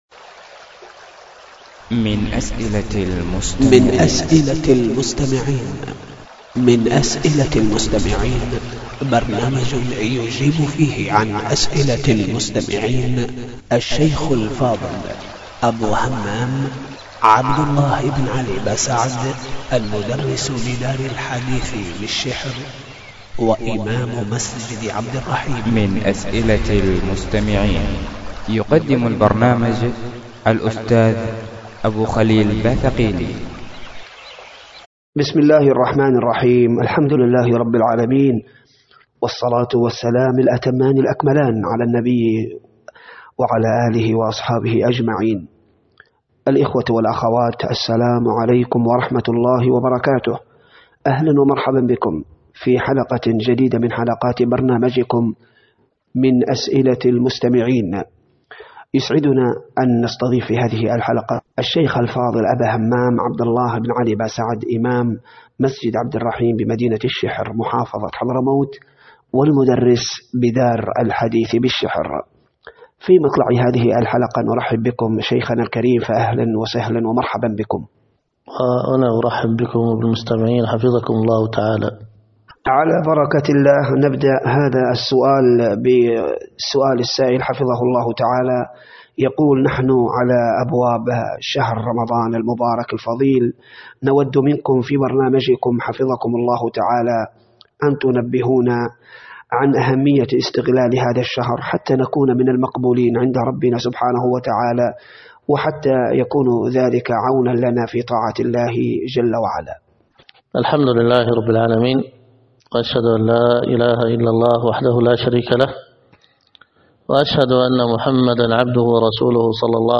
هاتفياً -
الدرس
هاتفياً -، الأربعاء 6 شوال 1431هـ بمسجد الحبش بالديس الشرقية .